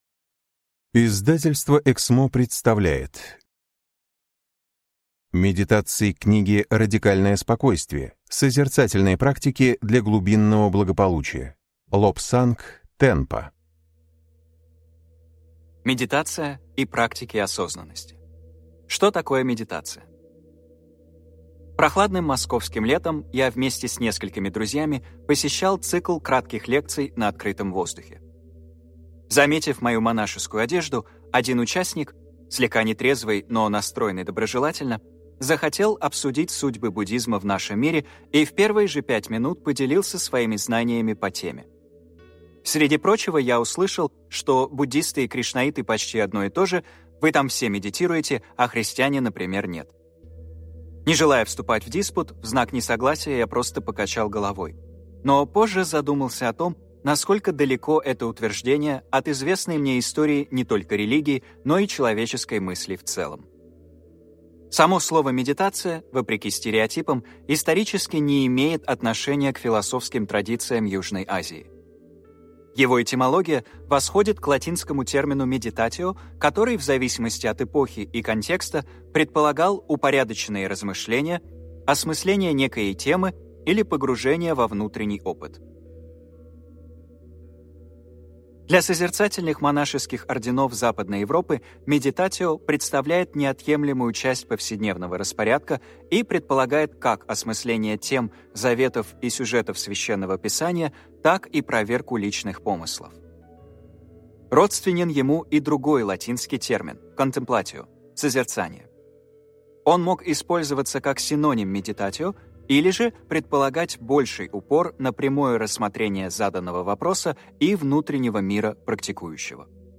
Аудиокнига Медитации к книге «Радикальное спокойствие» | Библиотека аудиокниг